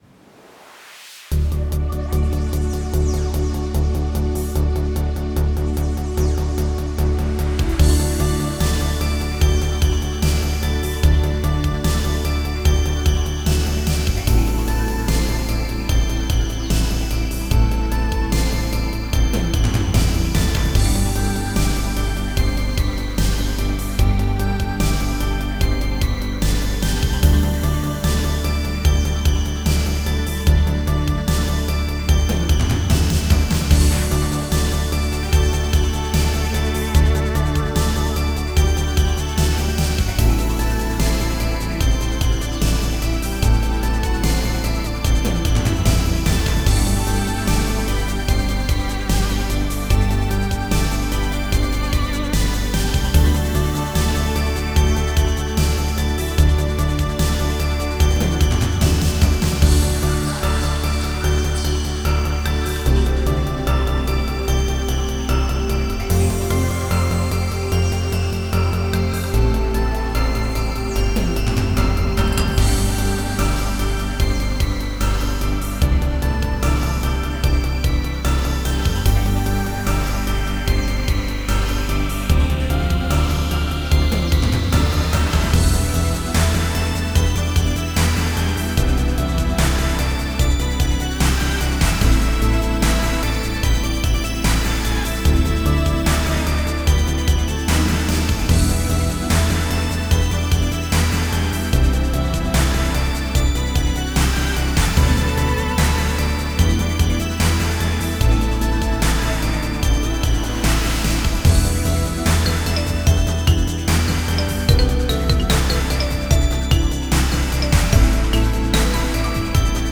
Stil: Synthpop
Awesomely gigantic Synth-Pop ballad
Beautiful leads and massive reverb drums
I love the marimba as well